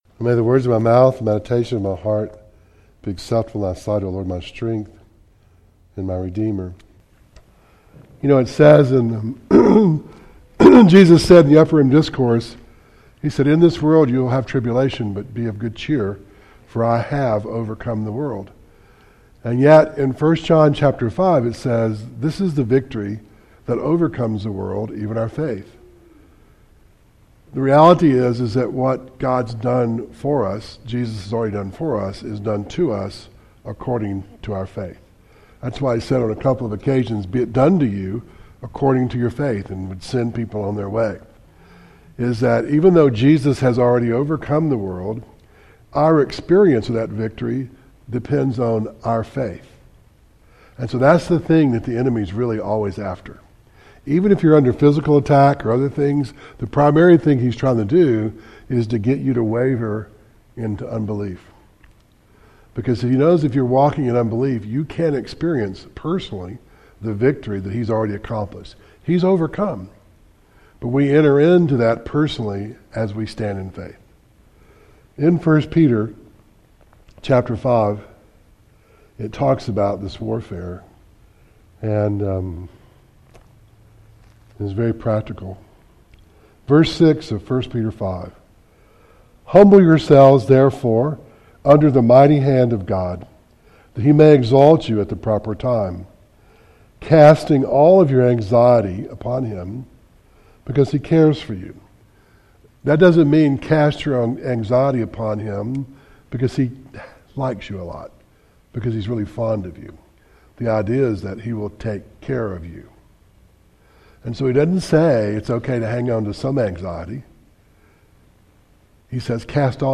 1 Timothy 1:12-19 Service Type: Devotional